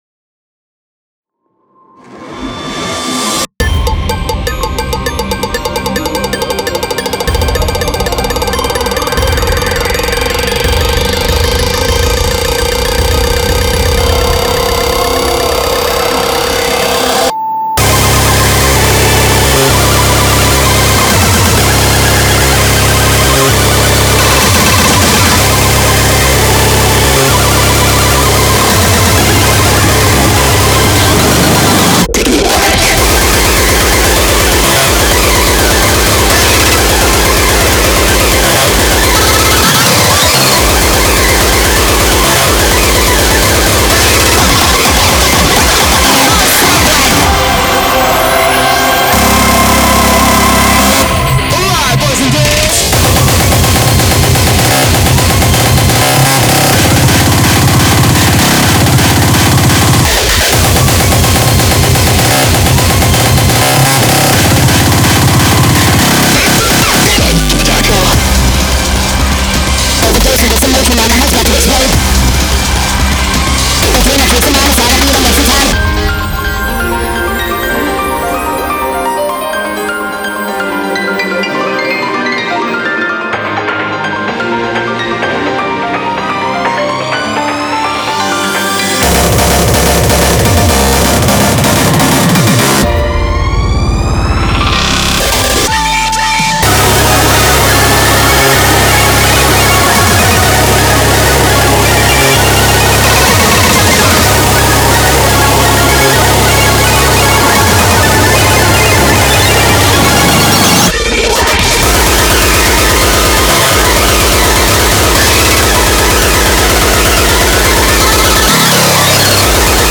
BPM252